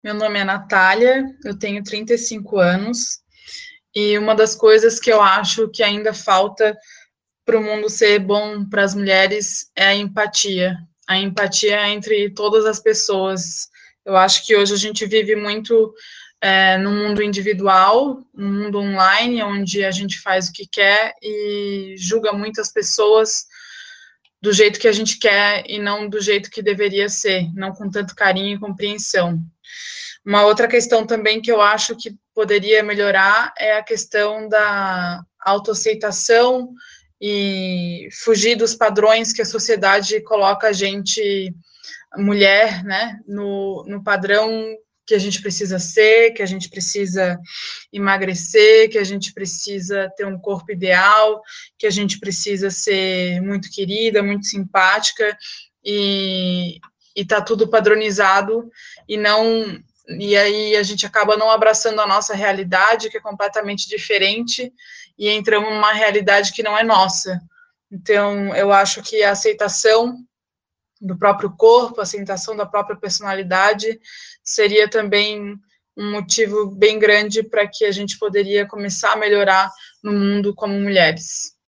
Ouvimos mulheres de várias idades, que viveram momentos diferentes dessa história, para saber o que ainda falta para o mundo se tornar um lugar bom para elas.